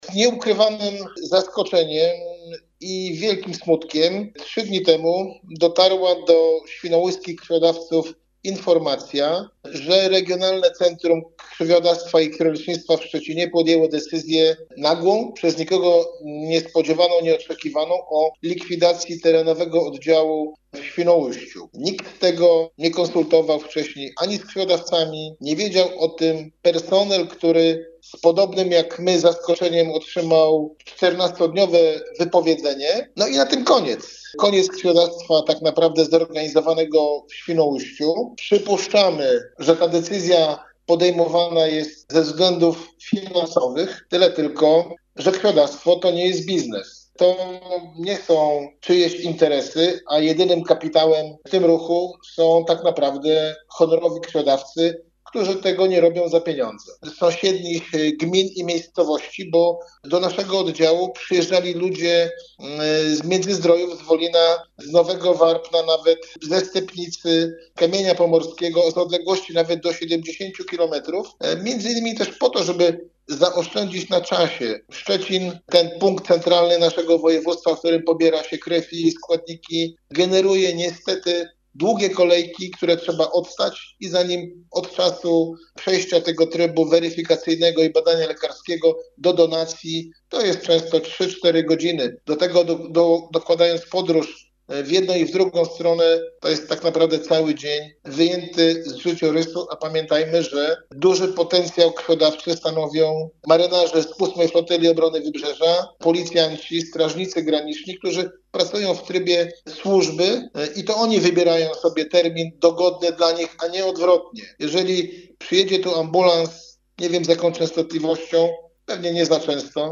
w rozmowie z Twoim Radiem